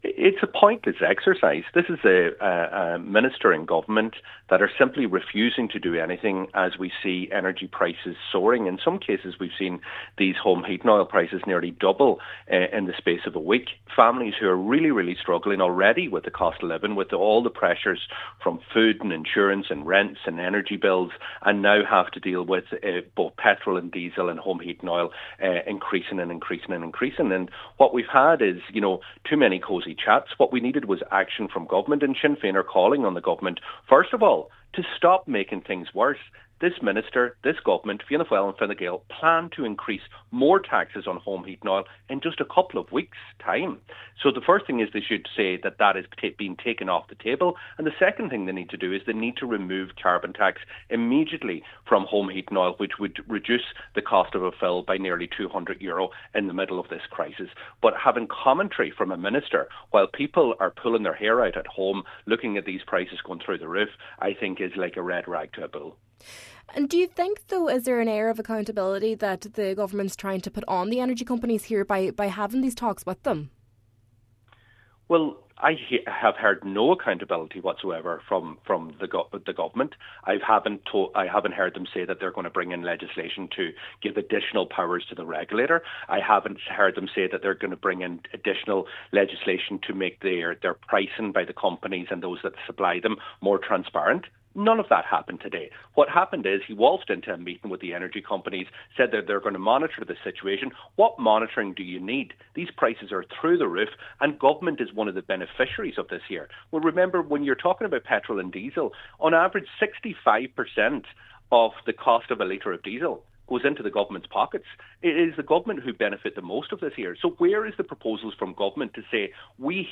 Deputy Pearse Doherty says he believes the government has the power to reduce the price but simply isn’t acting: